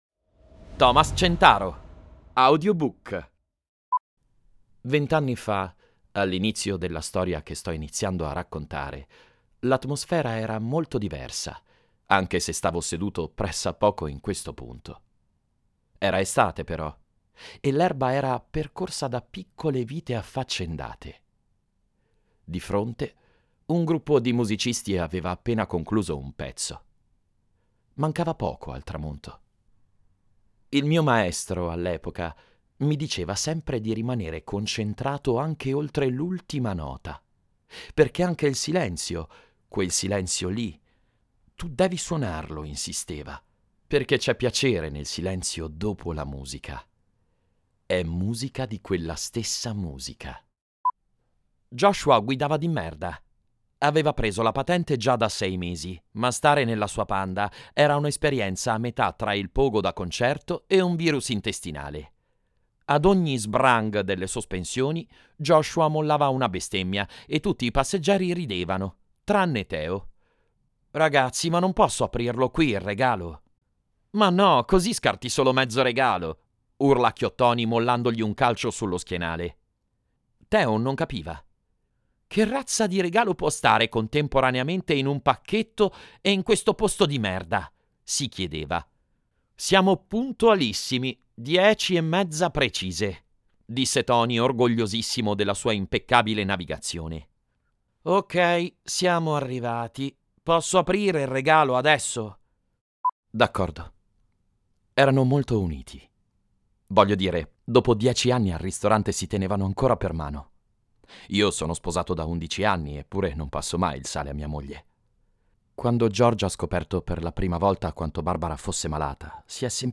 NARRATORE DI AUDIOLIBRI